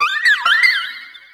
Grito de Comfey.ogg
Grito_de_Comfey.ogg